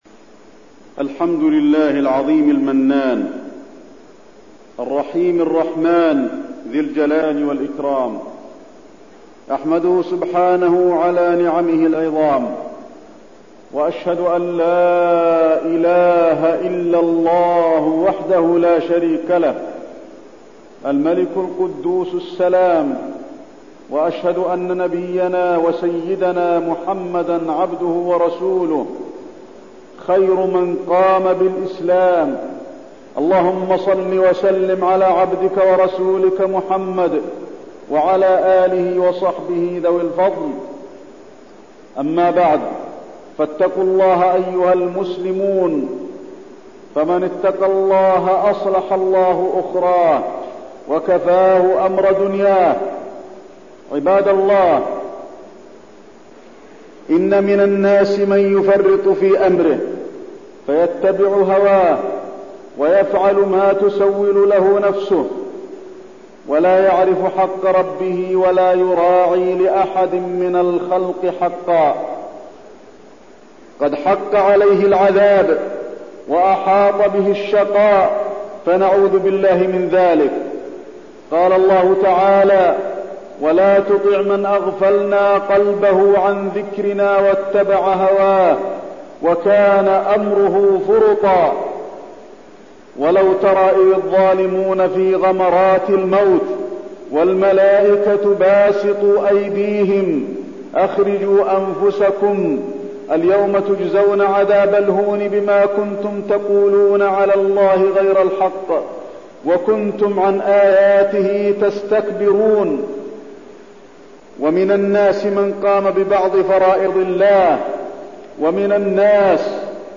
تاريخ النشر ٢٨ محرم ١٤٠٩ هـ المكان: المسجد النبوي الشيخ: فضيلة الشيخ د. علي بن عبدالرحمن الحذيفي فضيلة الشيخ د. علي بن عبدالرحمن الحذيفي اتباع الهوى